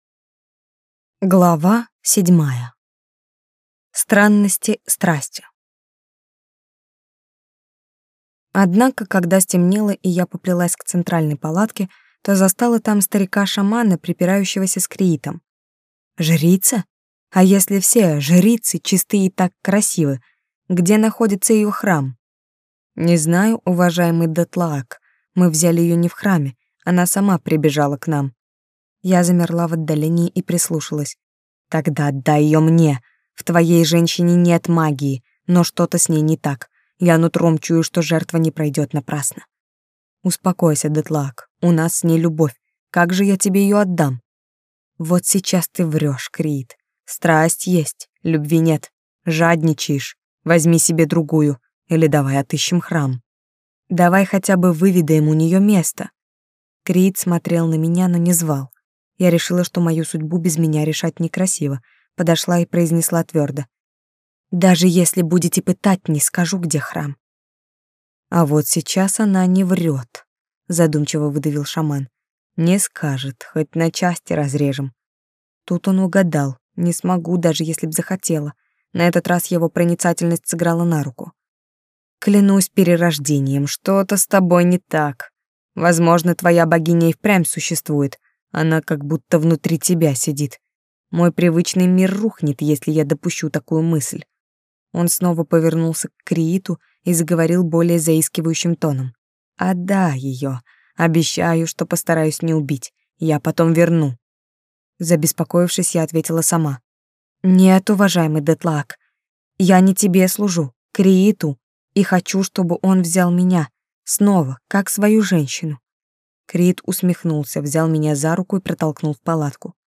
Aудиокнига Стать последней